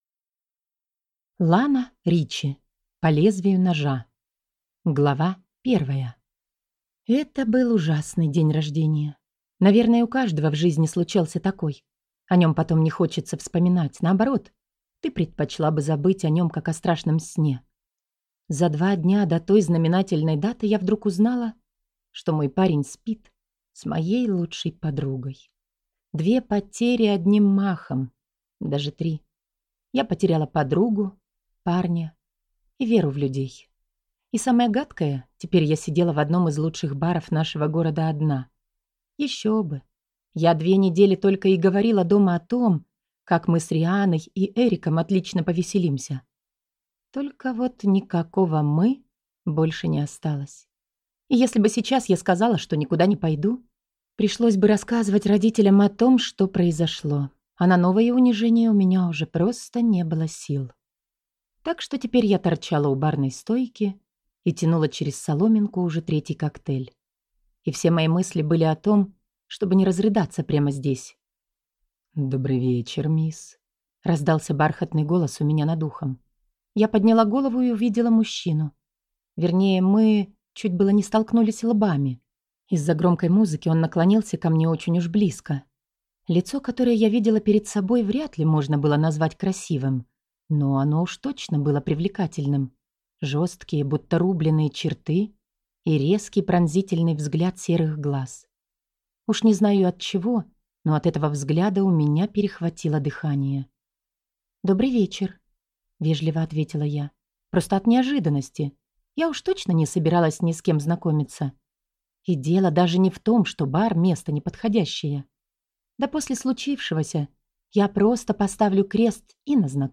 Аудиокнига По лезвию ножа | Библиотека аудиокниг